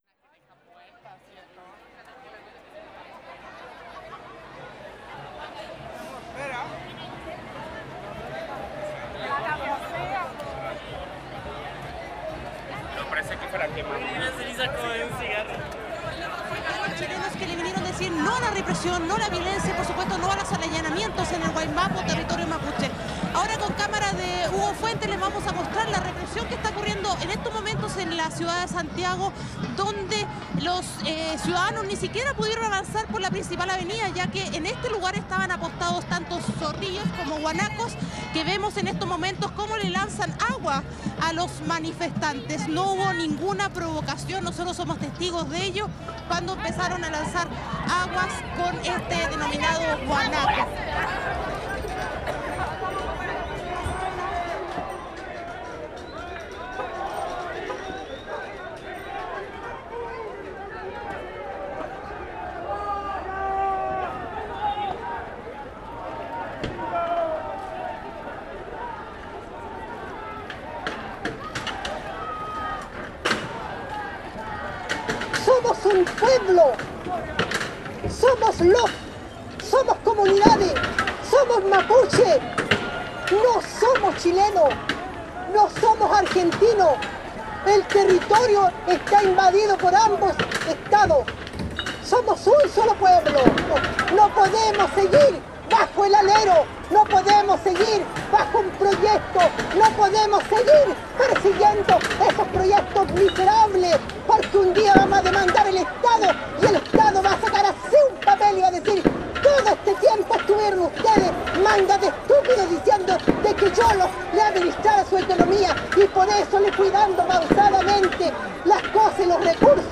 Sons de plusieurs manifestations dans la Plaza Italia et dans le Parque Bustamante tenues entre le 15 et le 28 novembre 2018 à Santiago